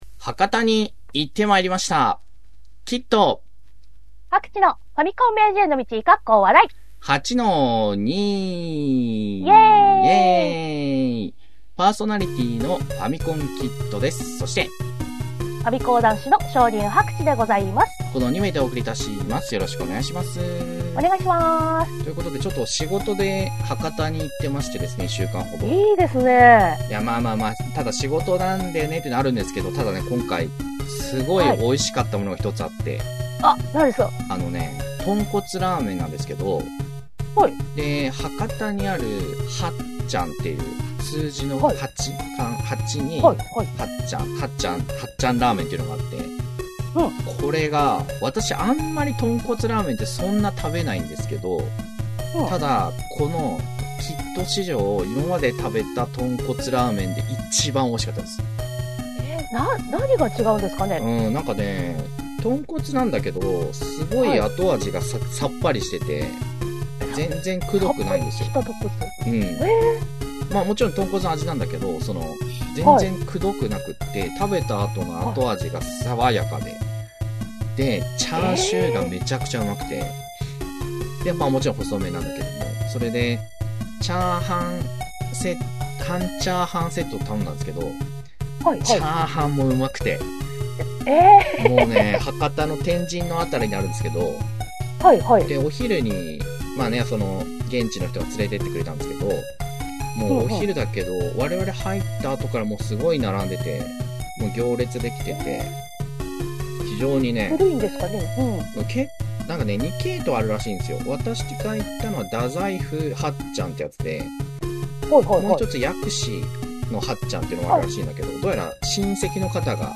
昭和っぽいテイストのインターネットラジオ